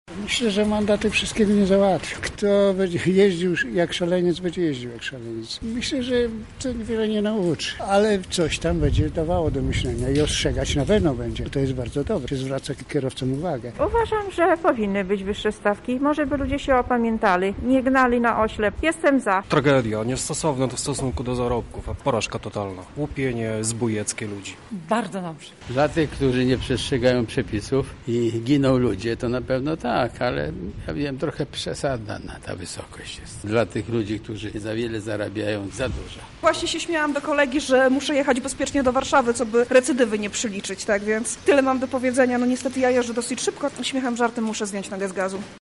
Zapytaliśmy mieszkańców Lublina, co myślą o wprowadzonych zmianach:
sonda